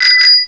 bicycle_bell.wav